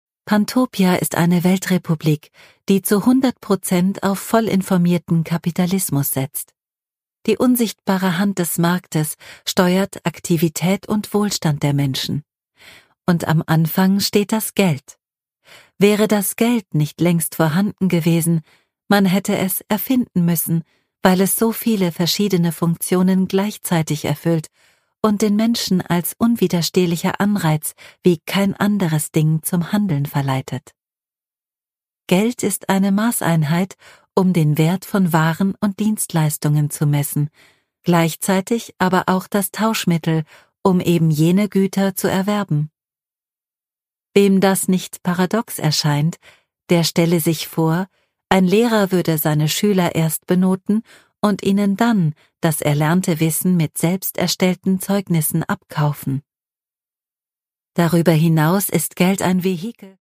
Theresa Hannig: Pantopia (Ungekürzte Lesung)
Produkttyp: Hörbuch-Download